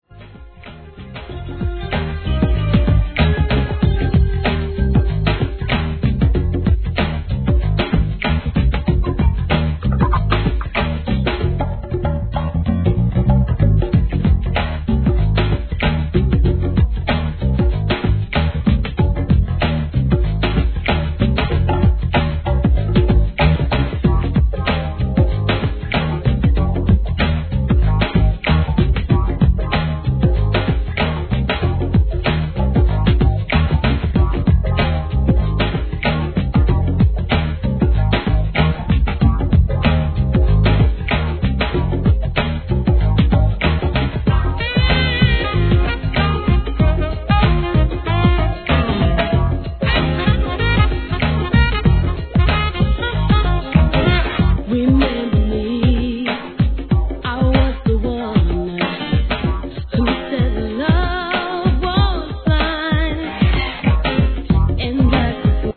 HIP HOP/R&B
手堅い洒落オツR&BのCLASSSICでしょう!!